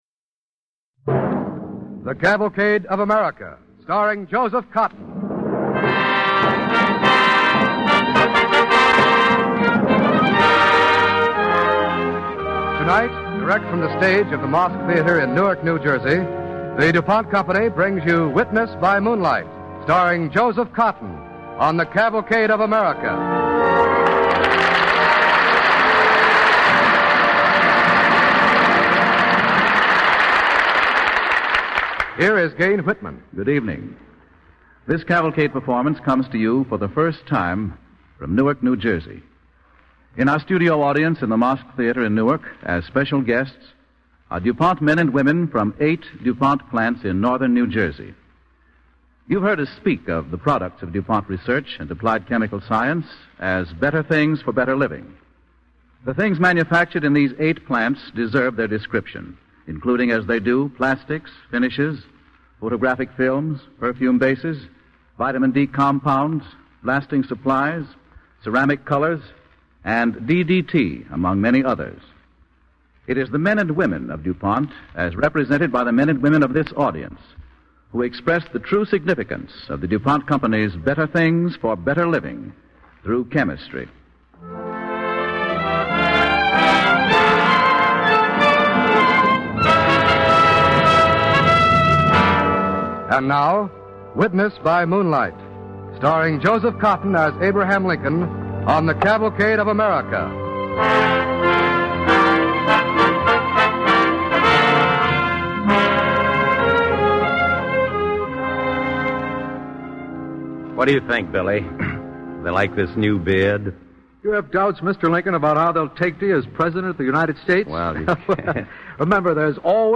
Cavalcade of America Radio Program
Witness by Moonlight, starring Joseph Cotten and Lawson Zerbe